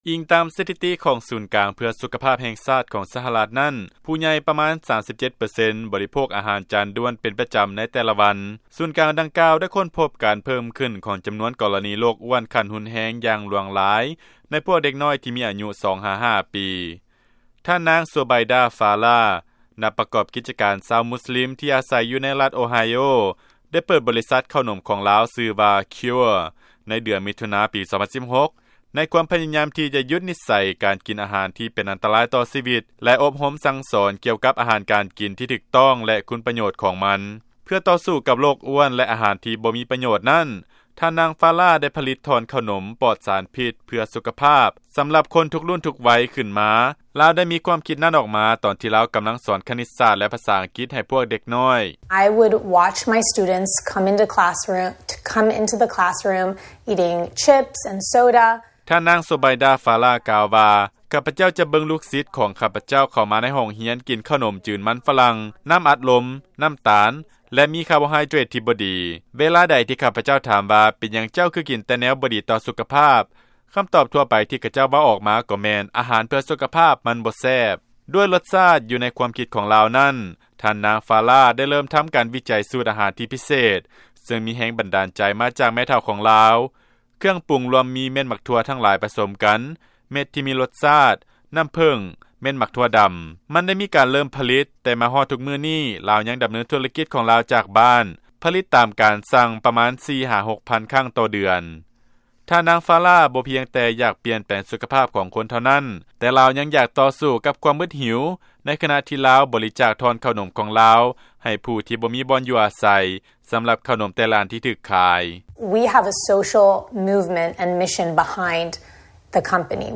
ຟັງລາຍງານ ນັກປະກອບ ກິດຈະການ ຫວັງວ່າເຂົ້າໜົມເພື່ອສຸຂະພາບ ຈະປ່ຽນນິໄສການກິນອາຫານທີ່ເປັນອັນຕະລາຍຕໍ່ສຸຂະພາບ